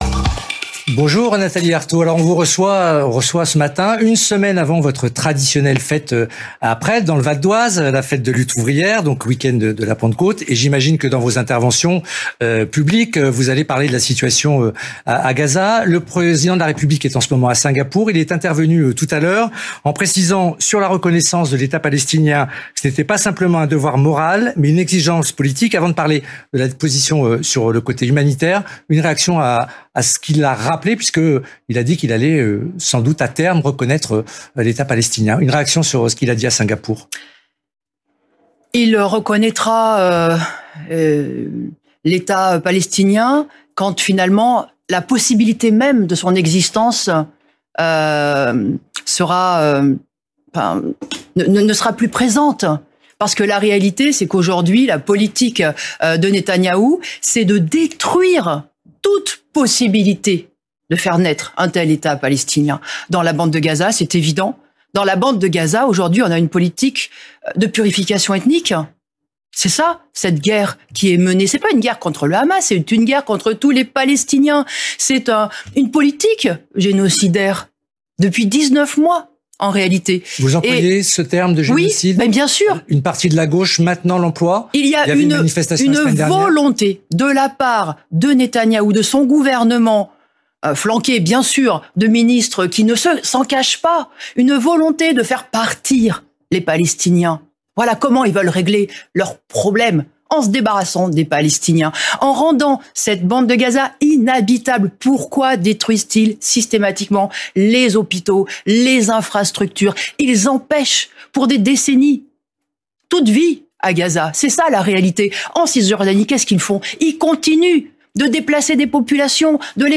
France Info télé : Interview de Nathalie Arthaud